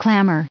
added pronounciation and merriam webster audio
861_clamor.ogg